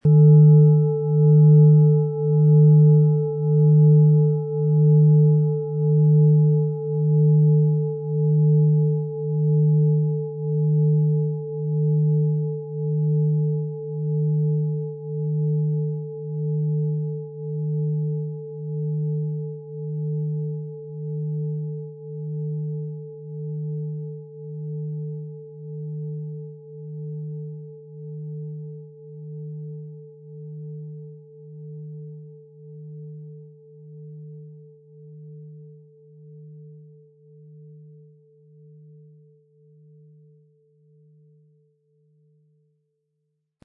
Planetenschale® Sanft und harmonisch sein & Strukturiert fühlen und sein mit Venus & Saturn, Ø 25,4 cm, 1900-2000 Gramm inkl. Klöppel
• Tiefster Ton: Saturn
PlanetentöneVenus & Saturn
MaterialBronze